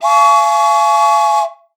Index of /90_sSampleCDs/Best Service ProSamples vol.42 - Session Instruments [AIFF, EXS24, HALion, WAV] 1CD/PS-42 AIFF Session Instruments/Percussion